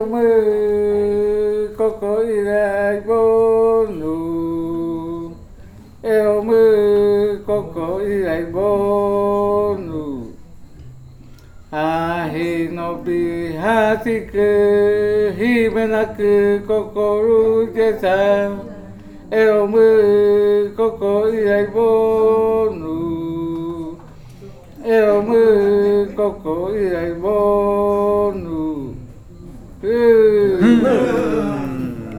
Leticia, Amazonas, (Colombia)
Grupo de danza Kaɨ Komuiya Uai
Canto fakariya de la variante Muinakɨ (cantos de la parte de abajo).
Fakariya chant of the Muinakɨ variant Downriver chants).